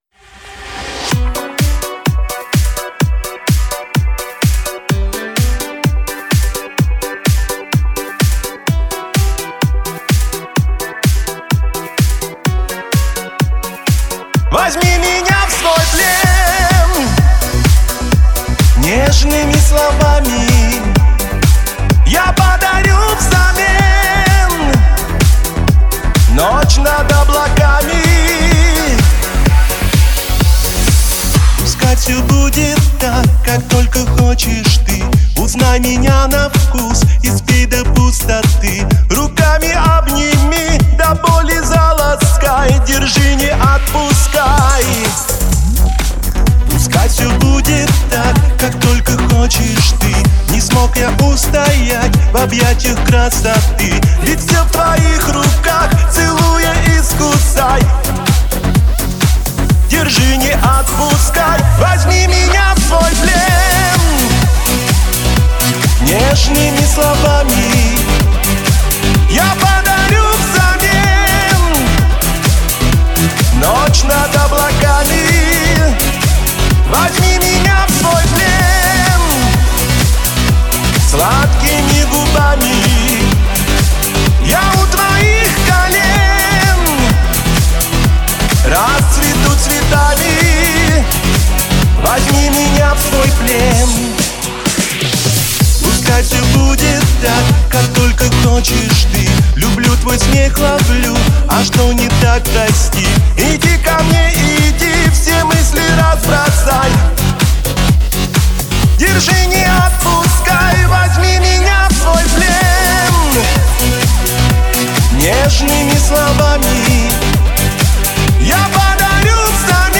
Не знаю, ребята, но у вас обоих с нотами что-то не так.
Песенка попсовая.)